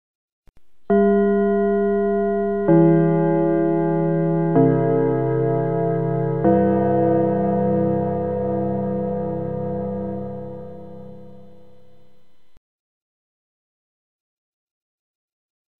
Schulklingel Gong
Schulgong Dreiklang Schulglocke Klingelton
Kategorie: Glockenpfeifen Geräusche
schulklingel-gong-de-www_tiengdong_com.mp3